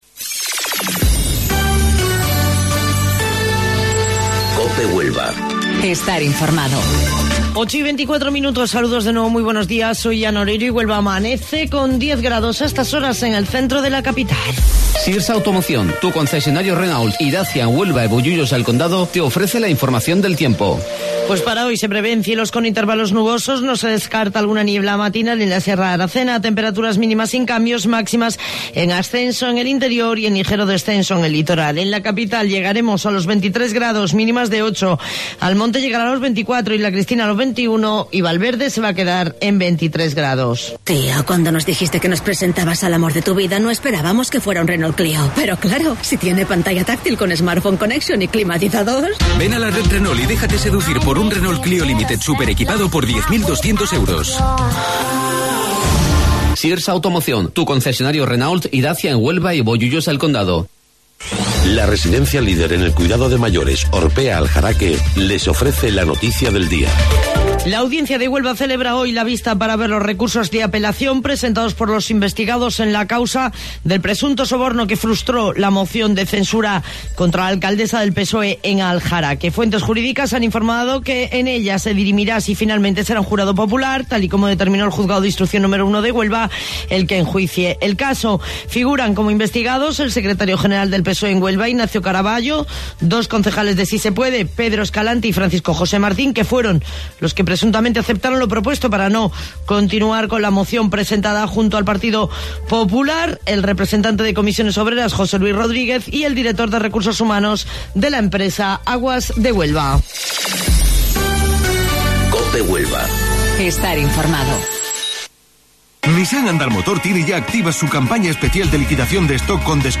AUDIO: Informativo Local 08:25 del 18 de Marzo